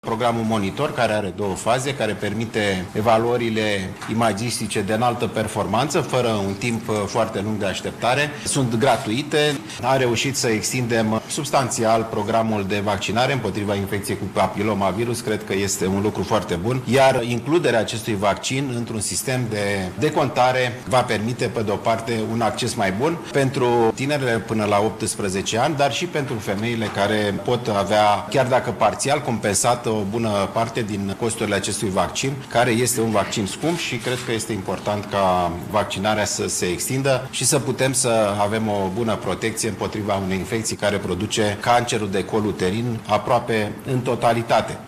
Alexandru Rafila a precizat că, vor fi făcute investiţii în domeniul oncologiei, iar serviciile de care vor beneficia pacienţii se vor deconta integral: